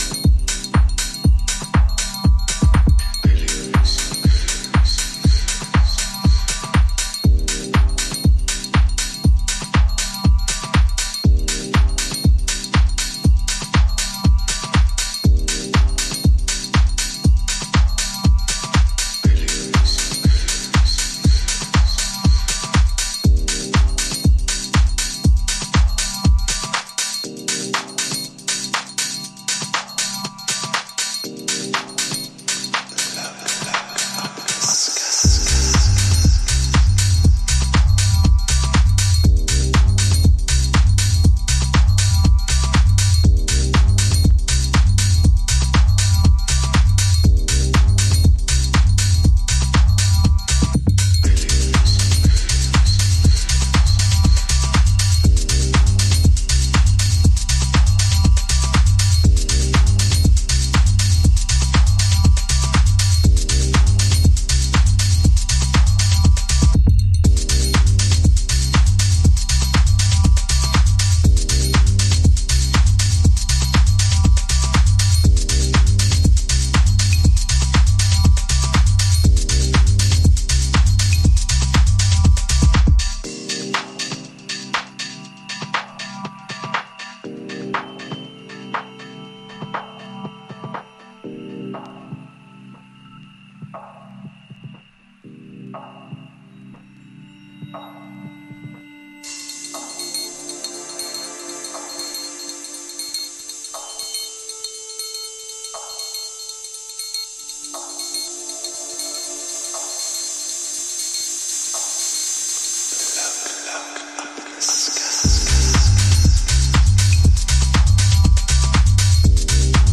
加速気味のビートにベースラインが粘るハウスリミックスA2もグッド。